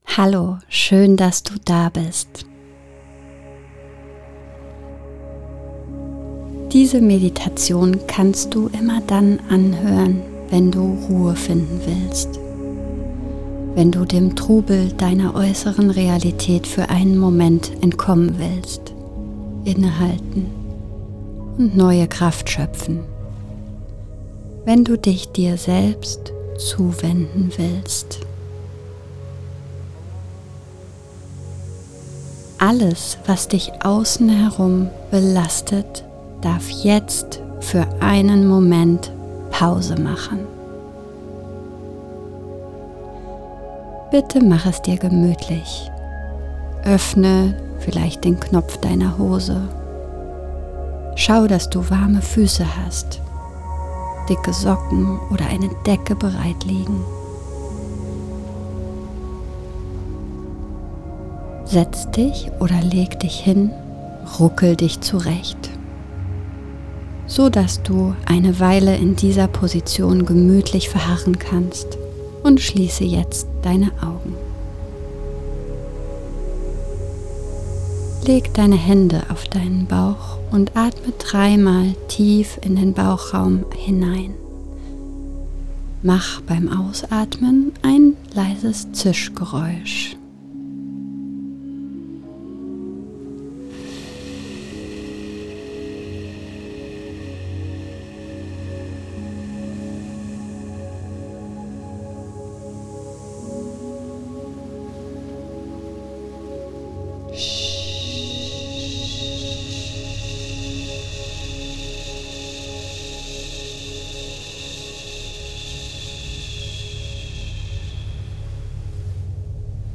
Meditation-Der-heilige-Hain.mp3